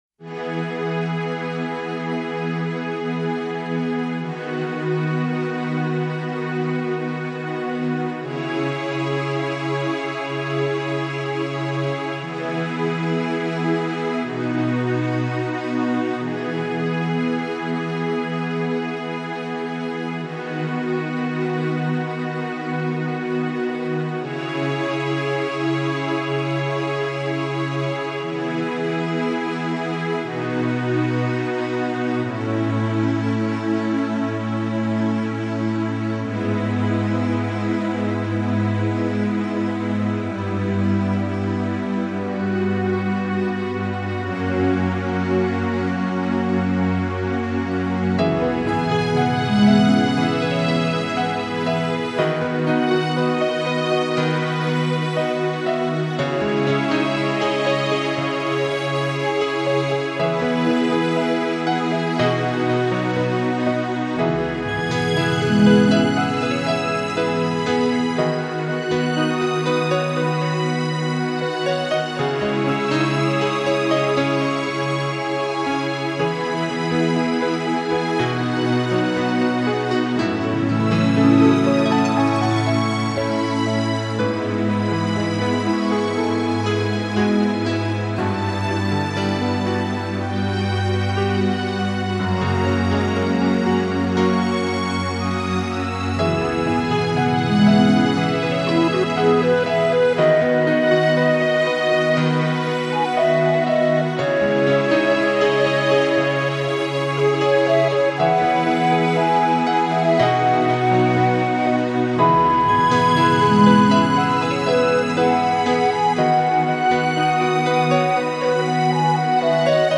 Жанр: Electronic, Lounge, Chill Out, Downtempo, Ambient